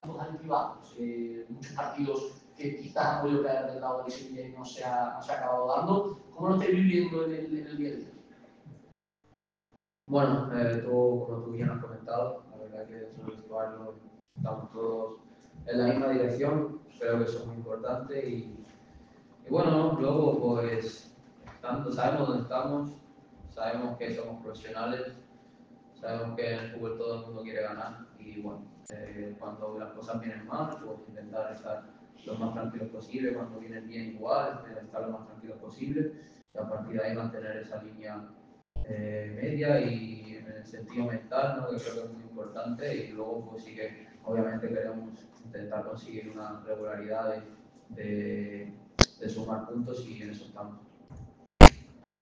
Gerard Fernández «Peque» volvió a situarse en el foco mediático tras comparecer en la sala de prensa del Estadio Jesús Navas.